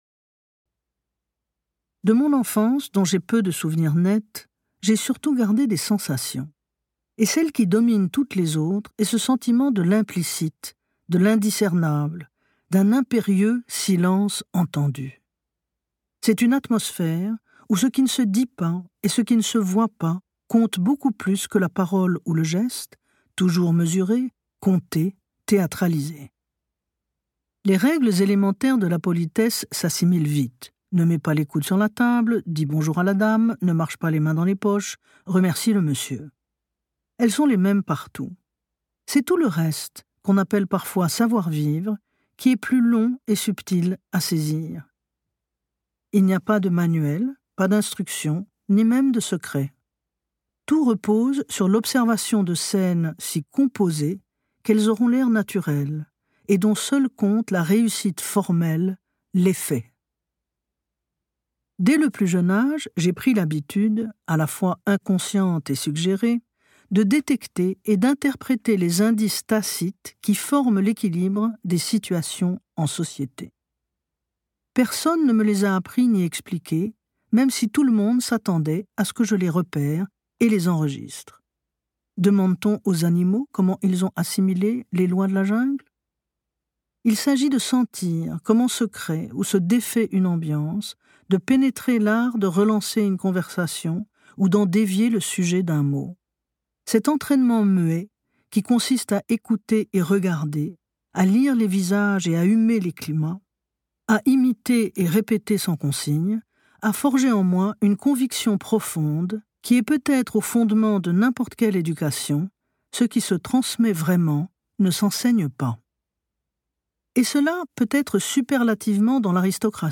Extrait gratuit - Proust, roman familial - Prix Médicis essai 2023 de Laure Murat